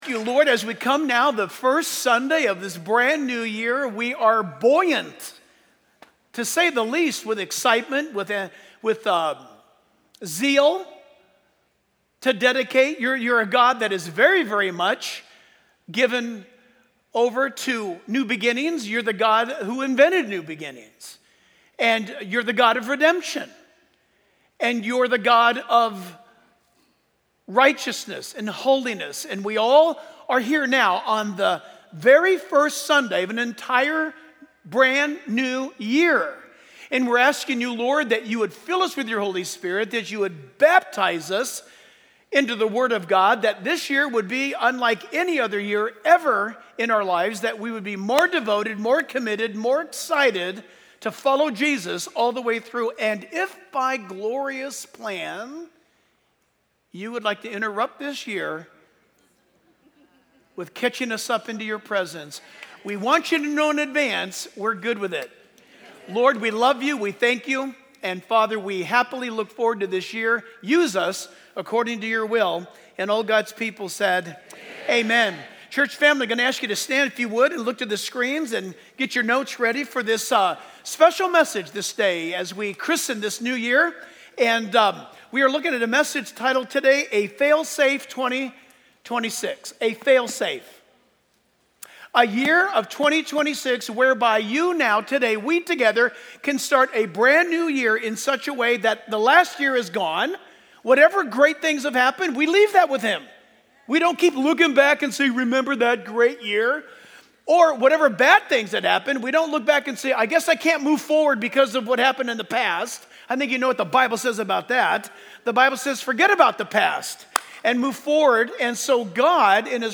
Sermon Overview